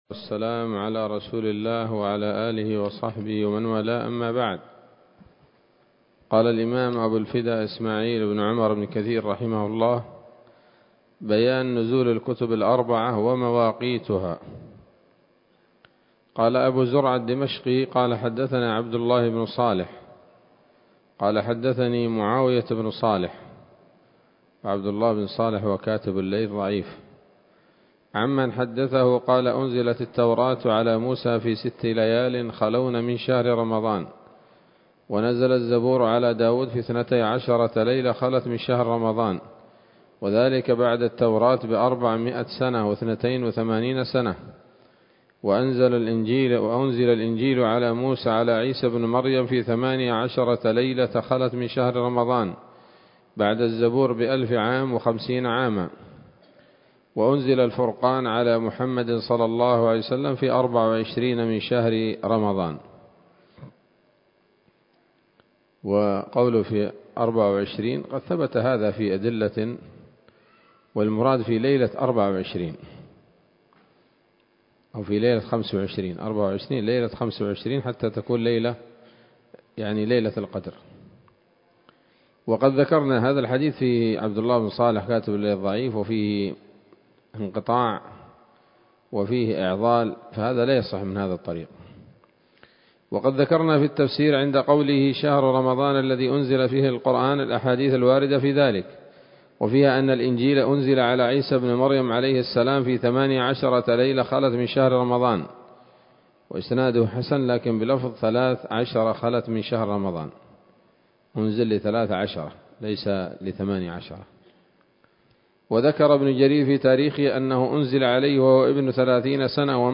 ‌‌الدرس السادس والأربعون بعد المائة من قصص الأنبياء لابن كثير رحمه الله تعالى